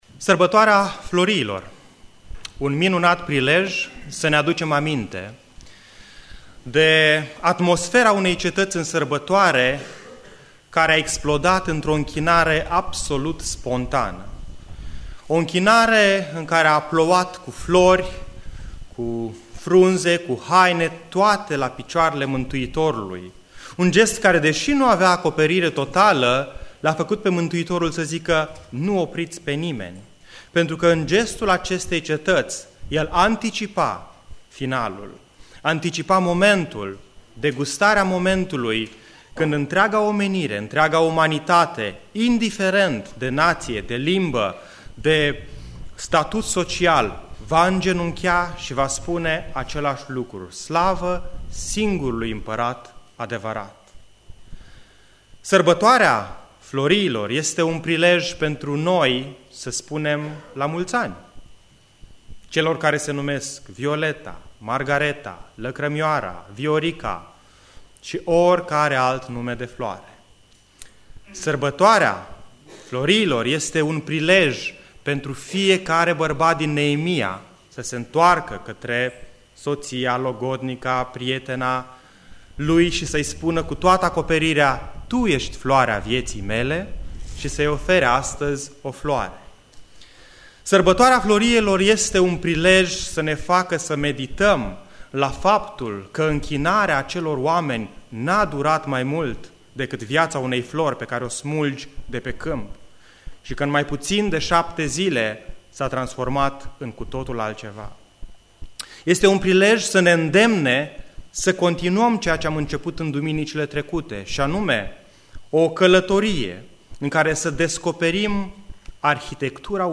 Descarca Predica Exegeza Chivotul Domnului Asculta online